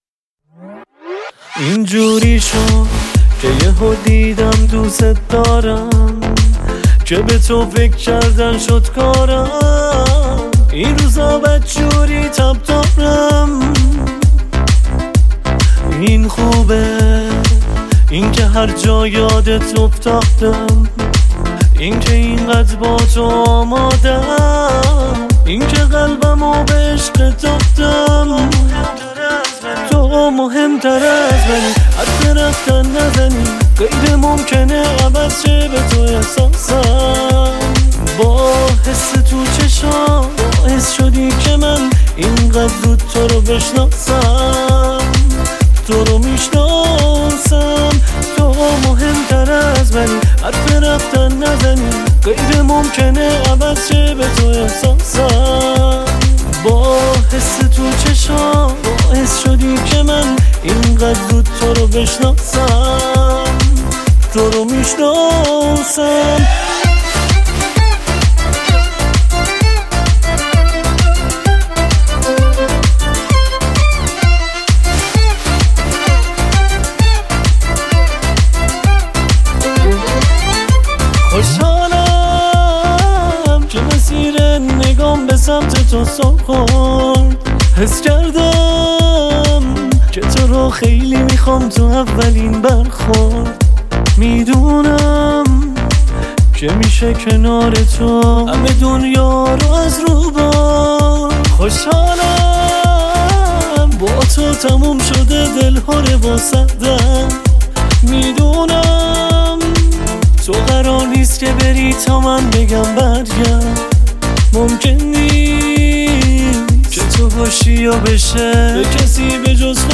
دانلود آهنگ شاد , آهنگ شاد ایرانی